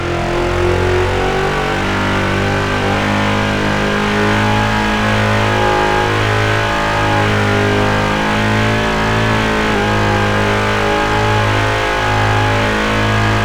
Index of /server/sound/vehicles/lwcars/buggy
fourth_cruise.wav